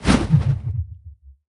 swish.ogg